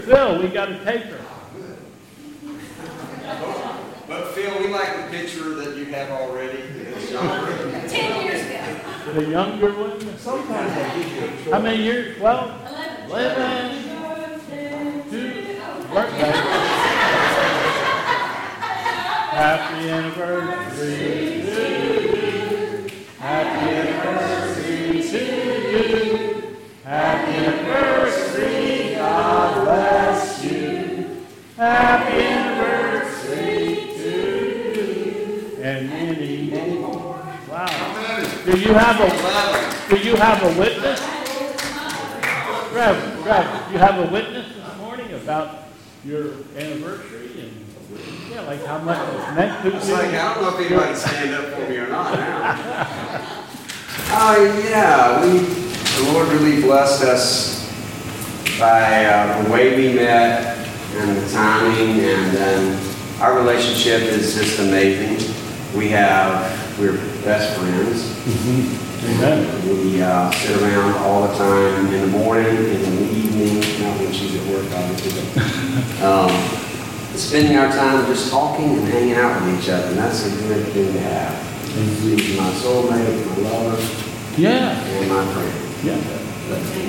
2021 Bethel Covid Time Service
Announcements